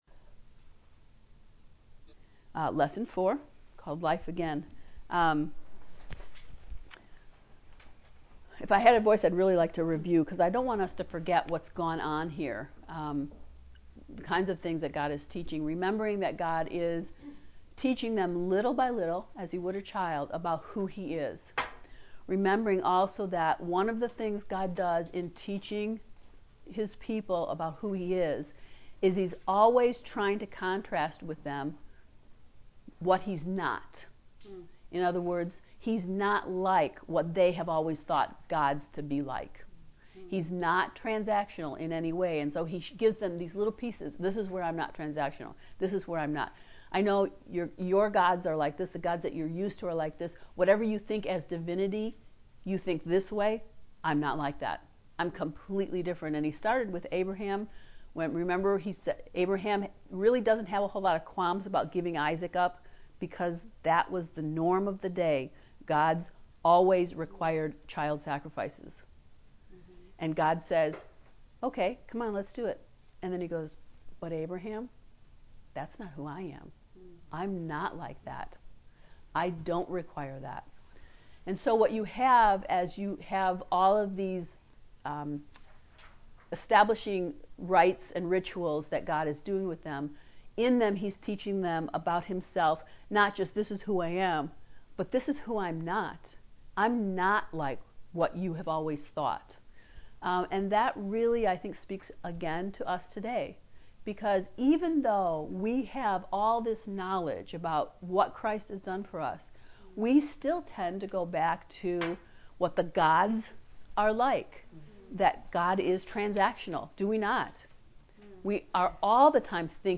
levlecture4.mp3